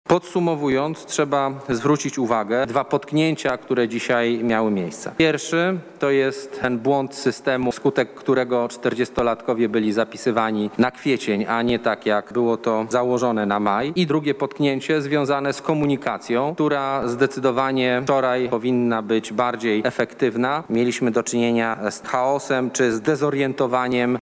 konferencja
Tłumaczenia w tej kwestii przedstawił szef kancelarii premiera i pełnomocnik rządu do spraw szczepień, Michał Dworczyk.